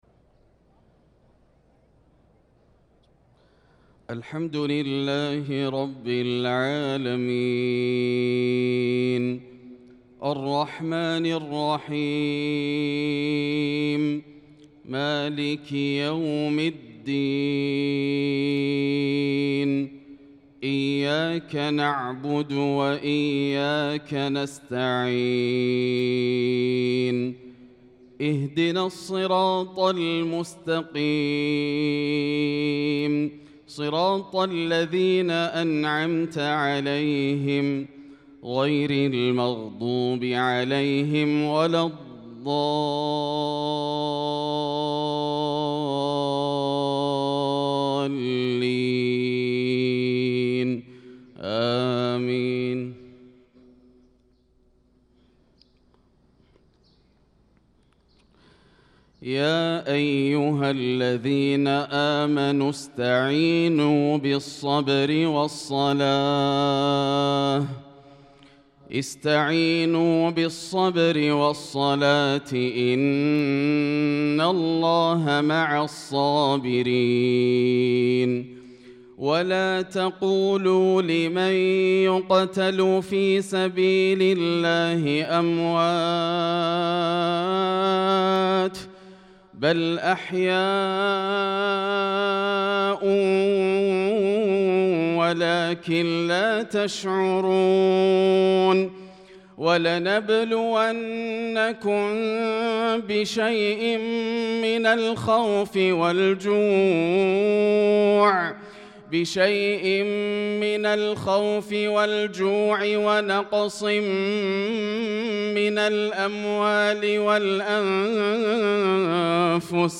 صلاة الفجر للقارئ ياسر الدوسري 8 ذو القعدة 1445 هـ
تِلَاوَات الْحَرَمَيْن .